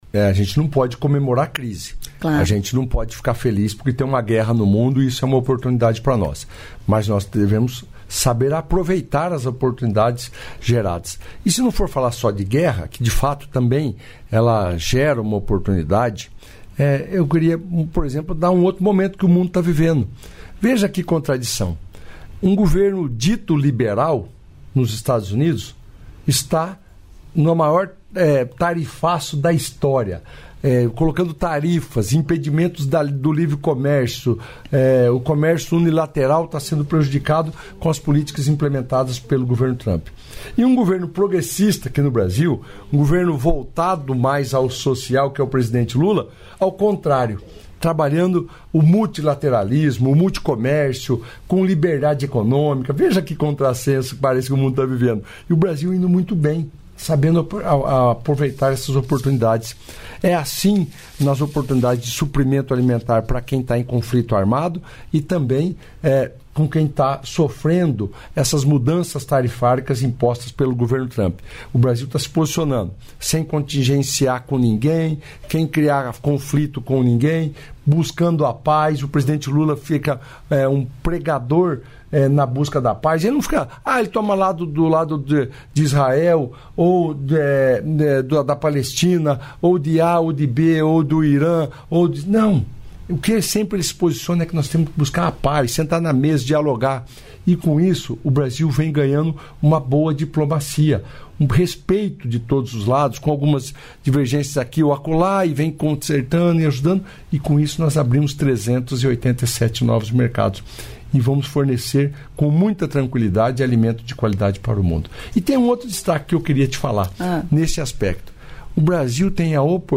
Trecho da participação do ministro da Agricultura e Pecuária, Carlos Fávaro, no programa "Bom Dia, Ministro" desta quinta-feira (3), nos estúdios da EBC em Brasília (DF).